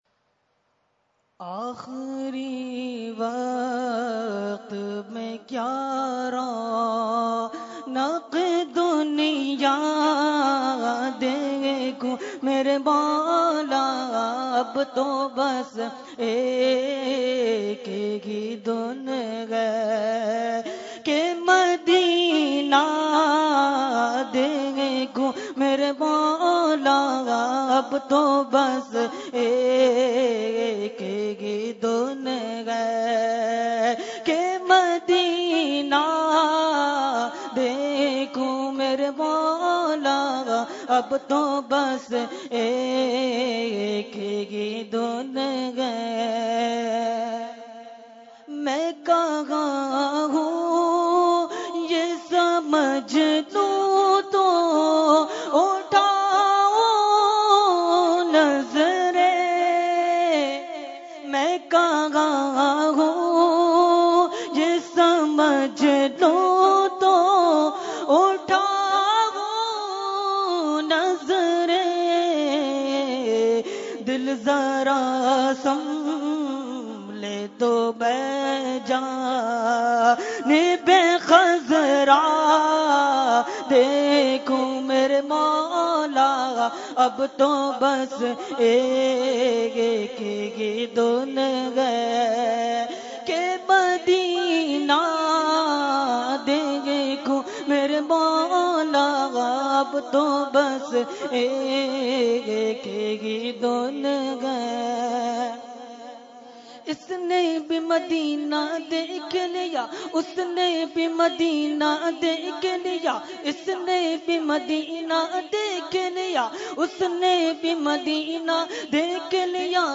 Category : Naat | Language : UrduEvent : Urs Makhdoome Samnani 2016